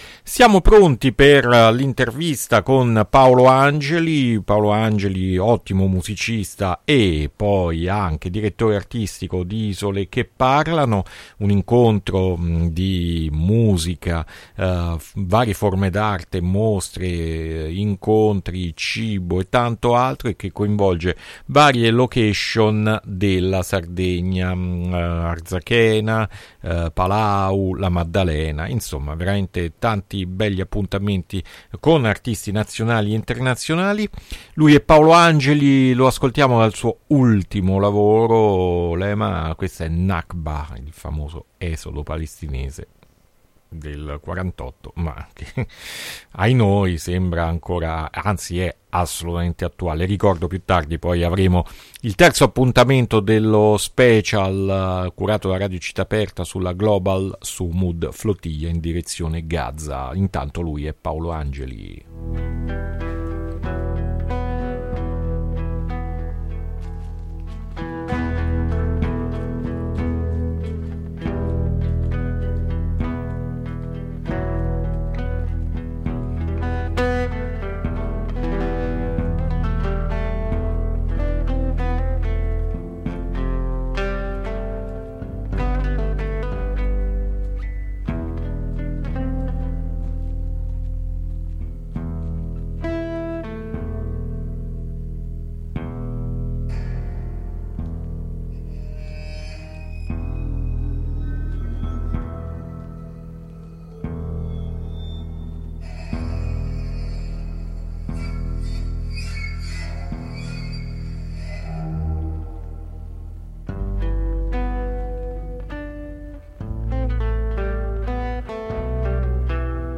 INTERVISTA RASSEGNA ISOLE CHE PARLANO A MERCOLEDI' MORNING 3-9-2025